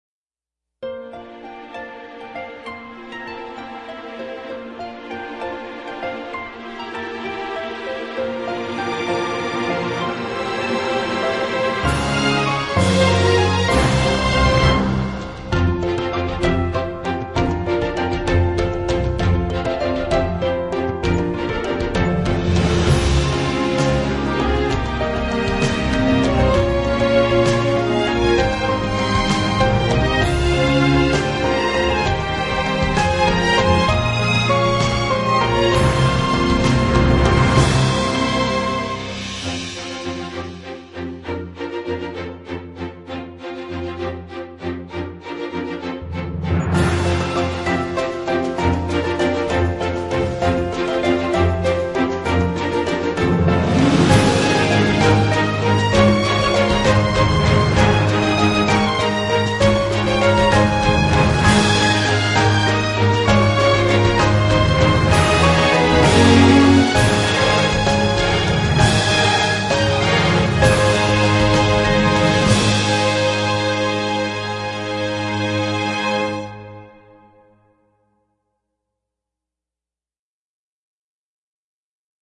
Instrumental with NO COMPOSER mentioned!!!!